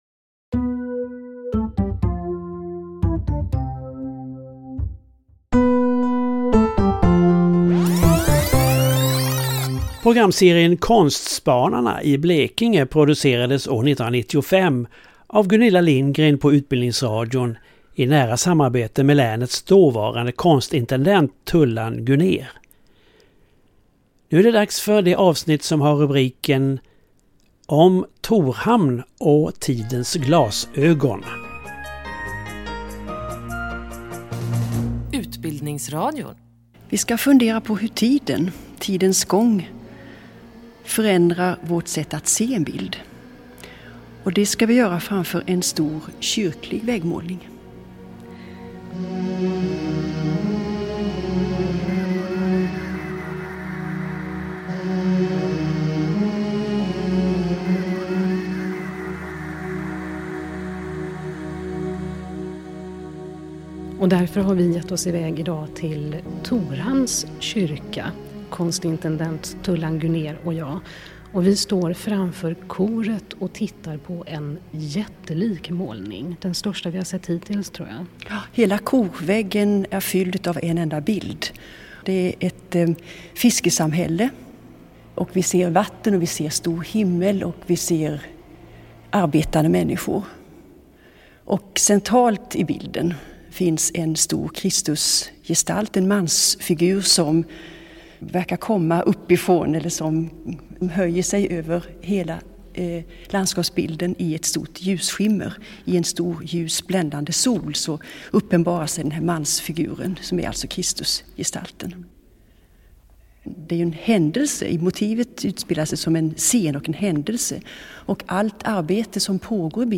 Programmen berättar om offentliga konstverk i Blekinge och sändes i Radio Blekinge hösten 1995. Del 7 Om Torhamn och tidens glasögon.